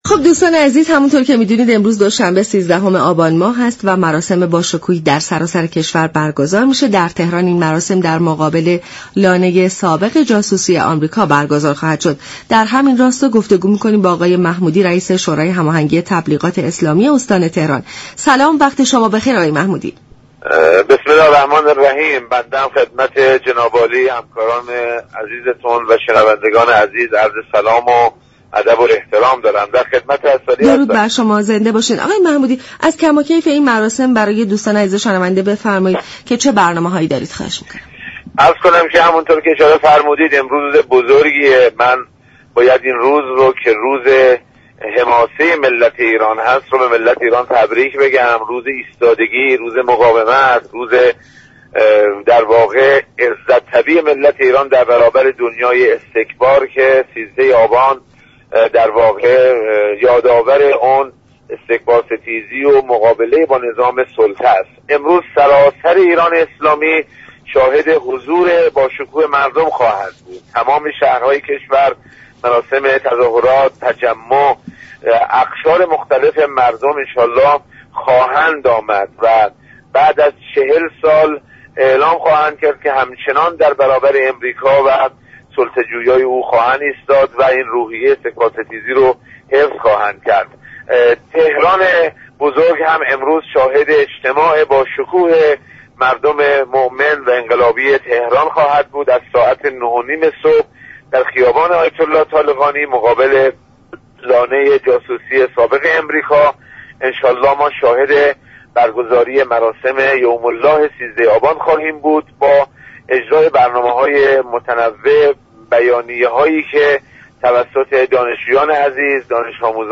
به گزارش شبكه رادیویی ایران، سید محسن محمودی رییس شورای هماهنگی تبلیغات اسلامی استان تهران در گفت و گو با برنامه «سلام صبح بخیر» رادیو ایران با بیان اینكه 13 آبان روز ایستادگی و مقاومت ملت ایران در برابر استكبار جهانی است، گفت: ایرانیان امروز با حضور خود در مراسم راهپیمایی 13 آبان اعلام می كنند پس از 40 سال همچنان در برابر سلطه جویی های آمریكا ایستاده اند و روحیه استكبار ستیزی شان را حفظ كرده اند.